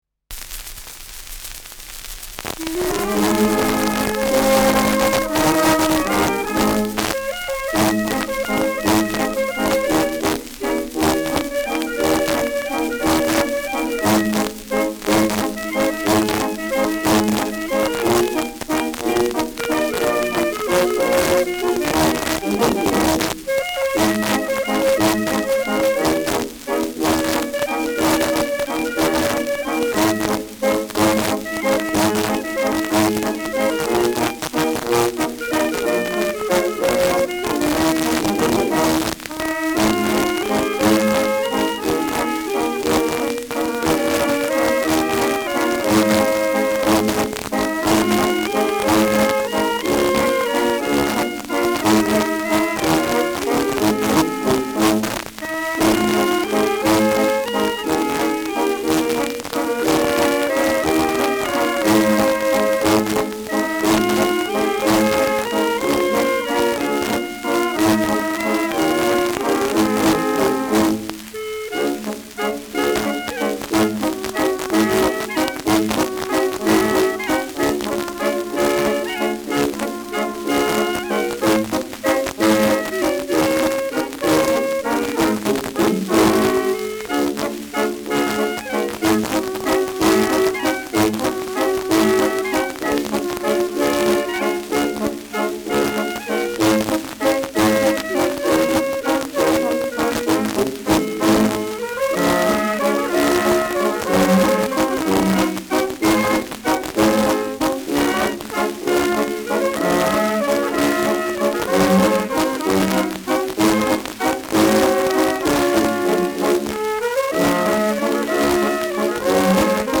Schellackplatte
präsentes Rauschen : präsentes Knistern : abgespielt : „Schnarren“ : leiert
Kapelle Jais (Interpretation)
[München] (Aufnahmeort)